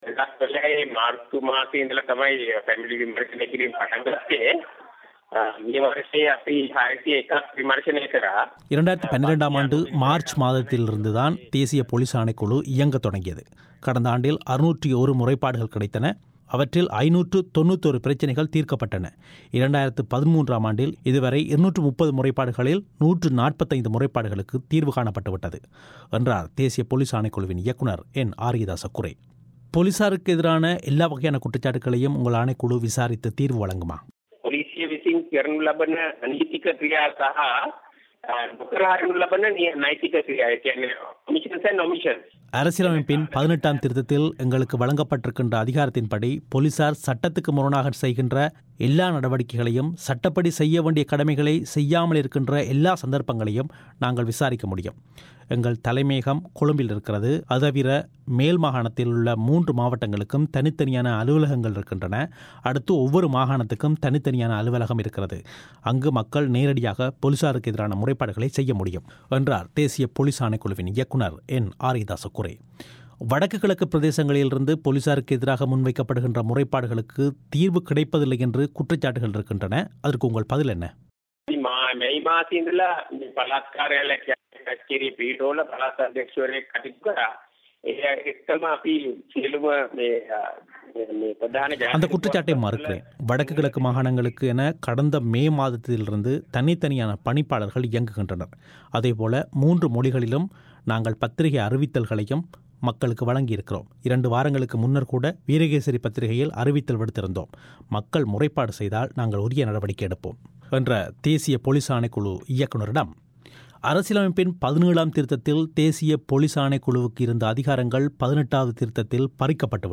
பொலிசார் சட்டப்படியான தமது கடமைகளை செய்யத் தவறினாலோ அல்லது சட்டத்தை மீறிச் செயற்பட்டாலோ அதுபற்றிய முறைப்பாடுகளை நாட்டின் பல பகுதிகளிலுமுள்ள தமது பிராந்திய அலுவலகங்களில் மக்கள் நேரடியாக முறைப்பாடு செய்ய முடியும் என்று தேசிய பொலிஸ் ஆணைக்குழுவின் பணிப்பாளர் ஆரியதாச குரே பிபிசி தமிழோசைக்கு அளித்த பிரத்தியேக செவ்வியில் தெரிவித்தார்.